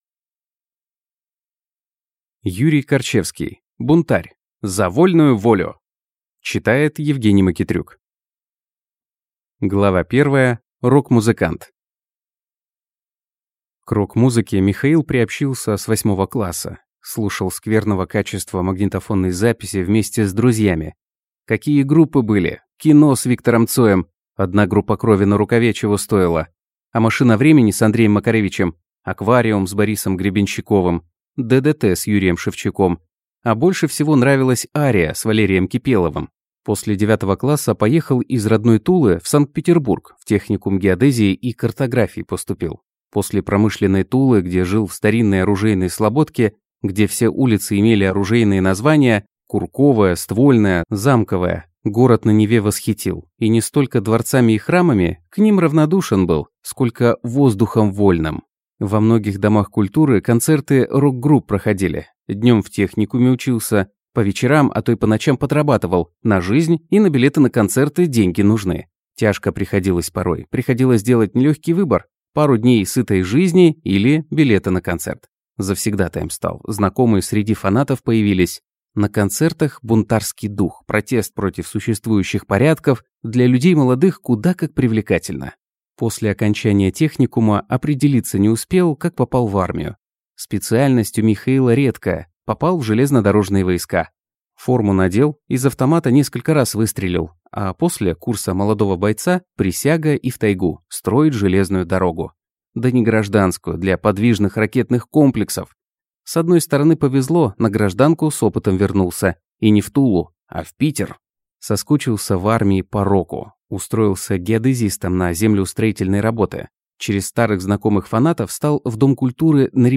Аудиокнига Бунтарь. За вольную волю!